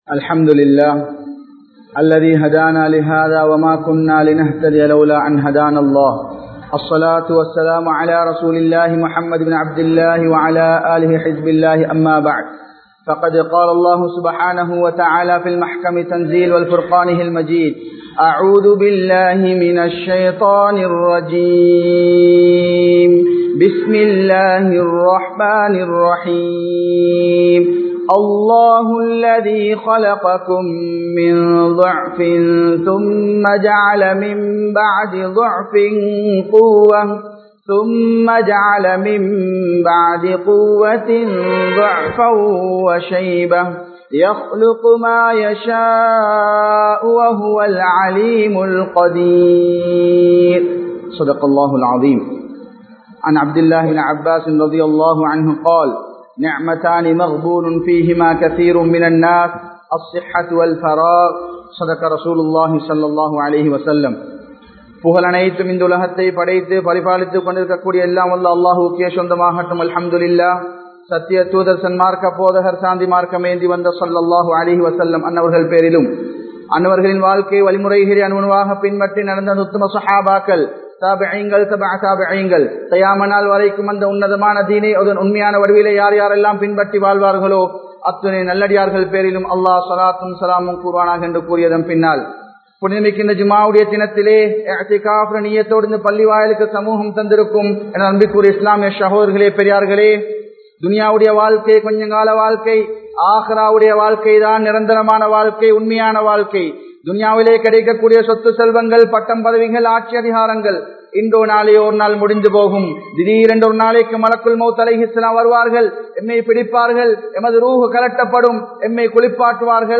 Vaalifaththai Veenakkaatheerhal (வாலிபத்தை வீணாக்காதீர்கள்) | Audio Bayans | All Ceylon Muslim Youth Community | Addalaichenai
Mallawapitiya Jumua Masjidh